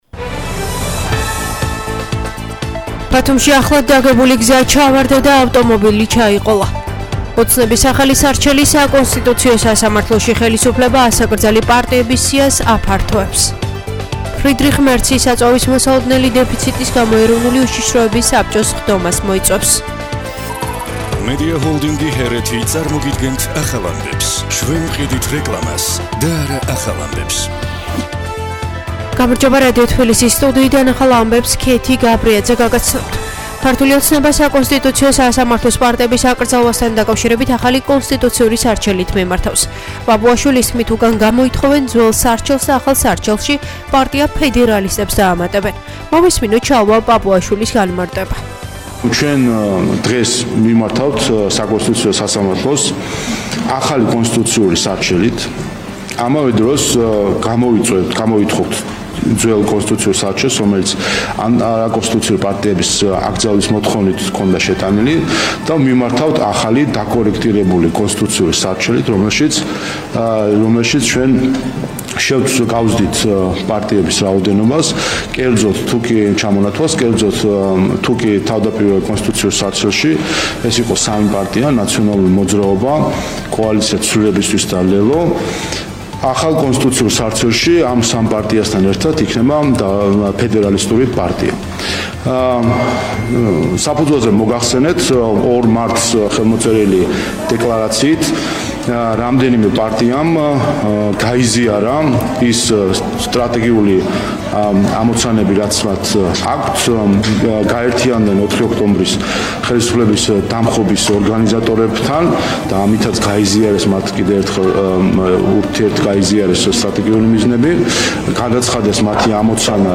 ახალი ამბები 14:00 საათზე